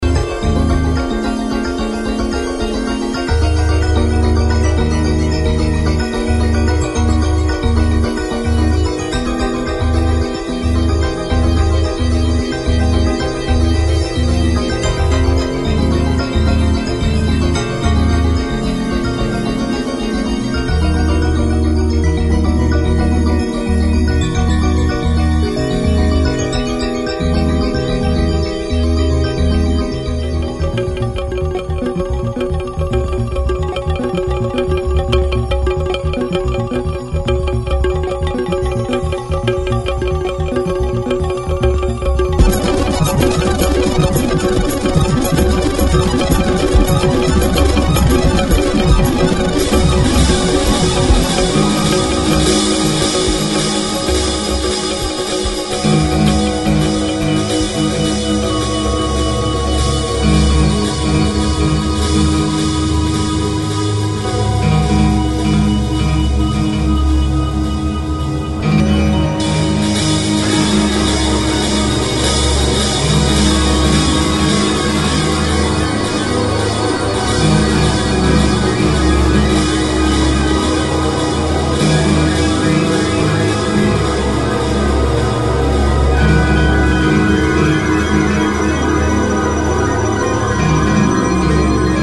As an electronic symphonic music creation